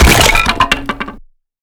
destroy_planks.wav